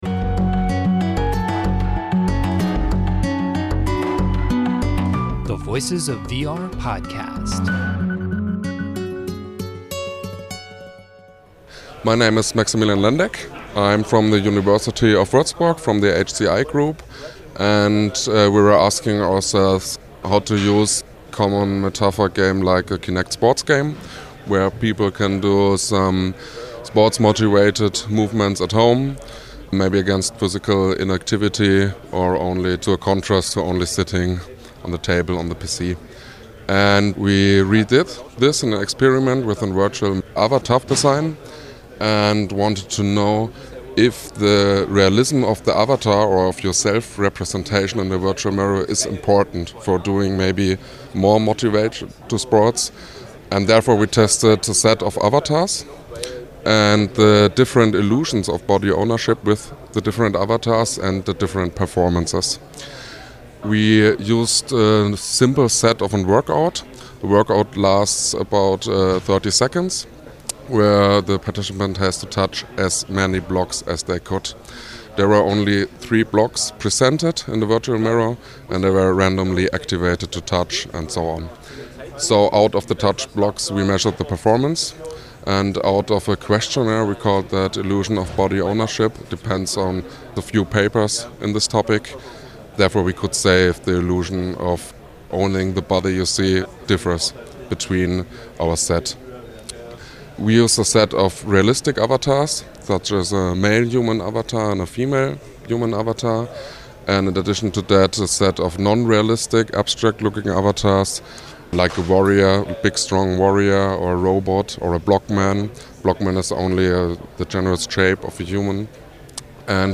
Theme music: “Fatality” by Tigoolio